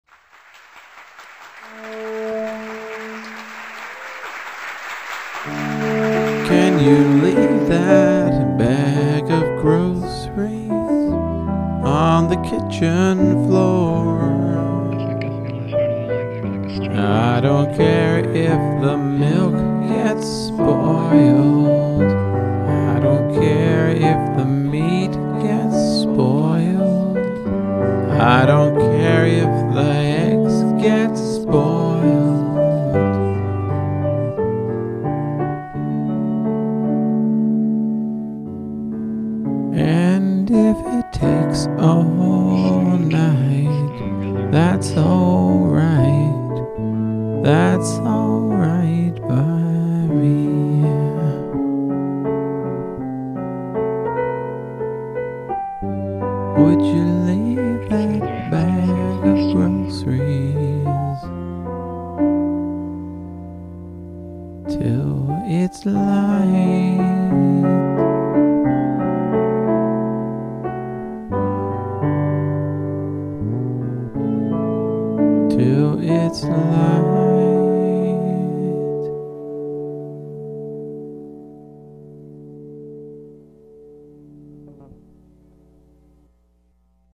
haunting notes and noises
rock ‘n roll
‘Sgot great gravel.